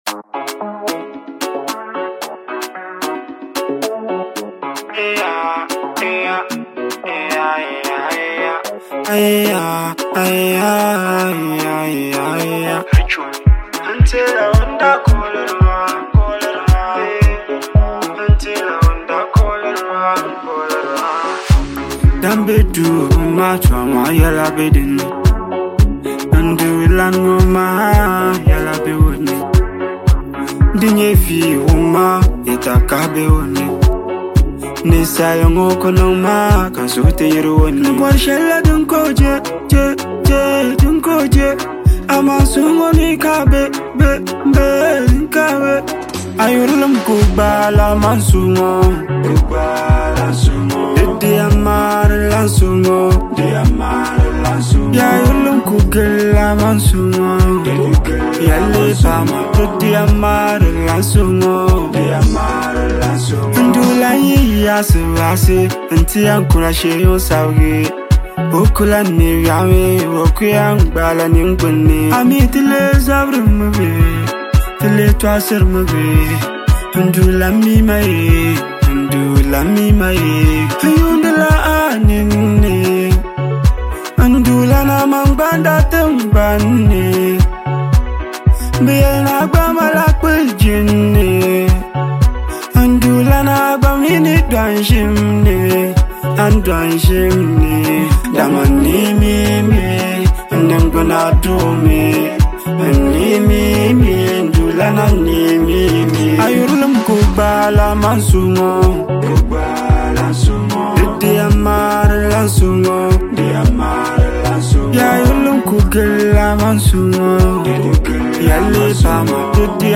With its soulful vibe and authentic expression